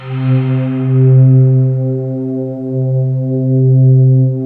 Index of /90_sSampleCDs/USB Soundscan vol.28 - Choir Acoustic & Synth [AKAI] 1CD/Partition C/08-FANTASY